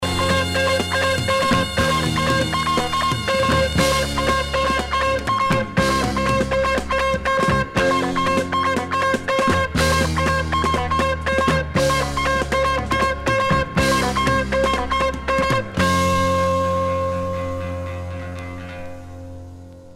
1. SOUNDTRACK >
B2はハモンド全開・ファンクネス全開の渋増テーマ。
# FUNK / DEEP FUNK# 60-80’S ROCK# 和モノブレイク